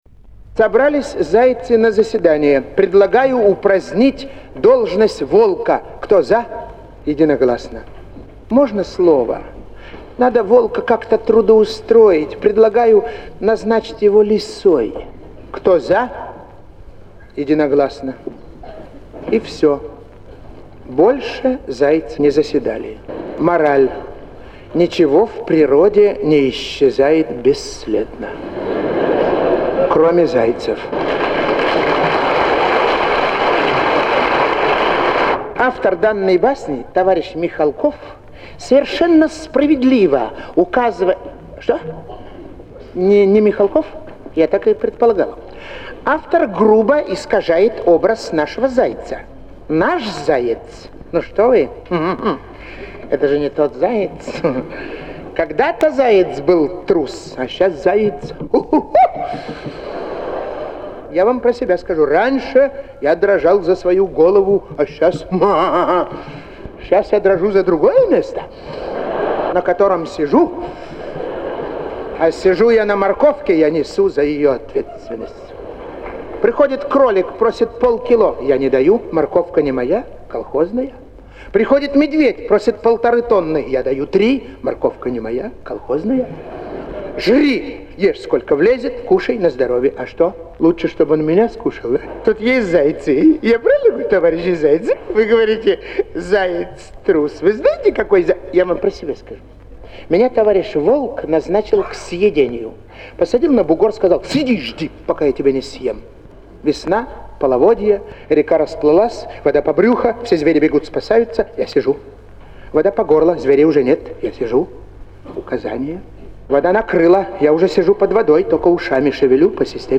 Словно не было года переездов, гостиниц, волнений – тот же искромётный темперамент, неповторимая райкинская интонация. Зал мечтает и негодует, взрывается от хохота и затихает, ловя каждое слово. Встречу Аркадия Райкина с земляками в Выборгском Доме культуры мы и записали на двенадцатой звуковой странице.
Звуковая страница 12 - фрагмент выступления А.Райкина. "Мораль: Ничто в природе не исчезает бесследно.